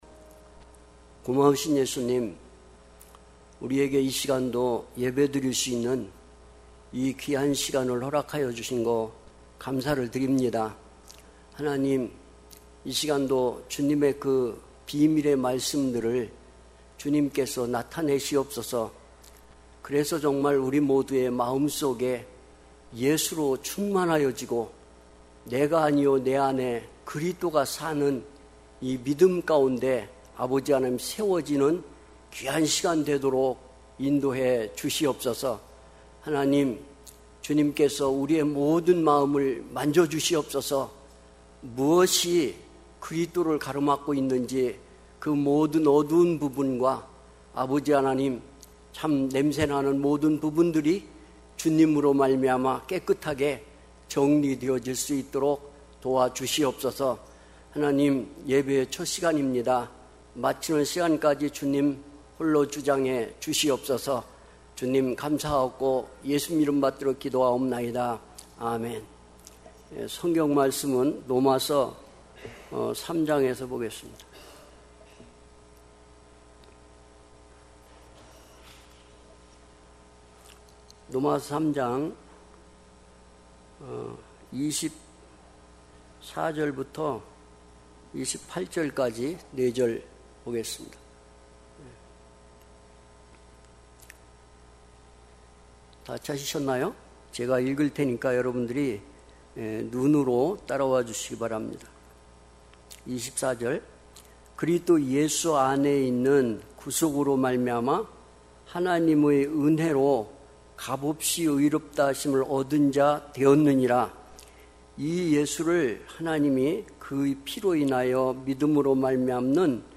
특별집회 - 로마서 3장 24-28절